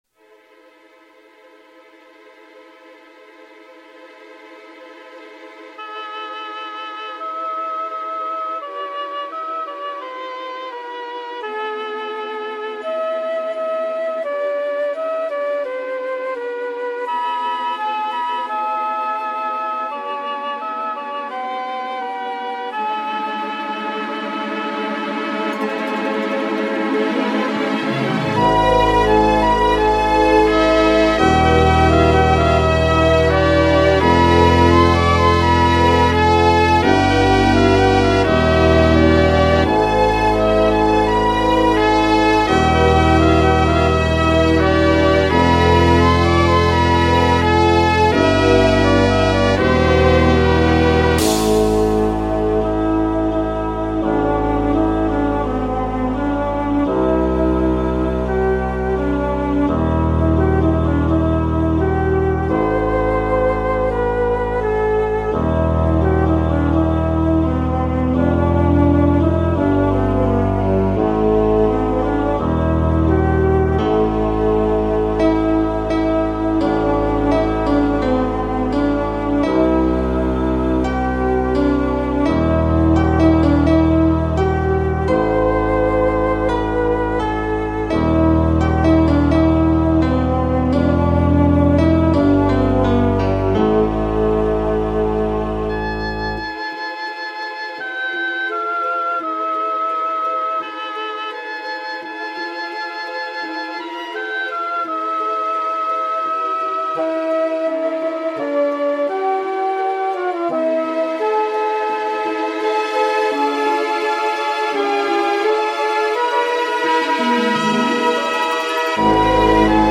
ナオコでいろんなMIDIを鳴らしてみた。
一部の楽器はすごくいい音が鳴るが、
それ以外は妙にチープだったり、そもそも鳴らなかったりする。